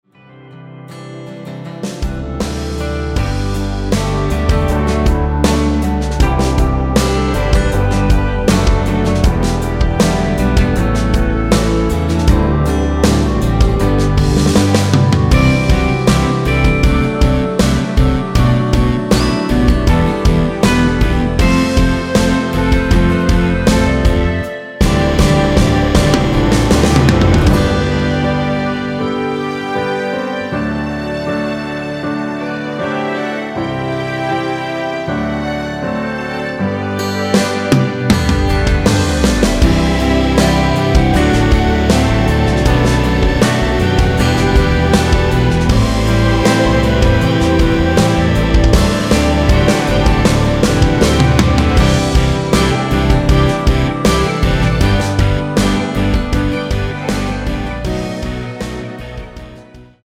원키에서(-4)내린 멜로디 포함된 편집 MR입니다.
축가로 잘 어울리는 곡
노래방에서 노래를 부르실때 노래 부분에 가이드 멜로디가 따라 나와서
앞부분30초, 뒷부분30초씩 편집해서 올려 드리고 있습니다.
중간에 음이 끈어지고 다시 나오는 이유는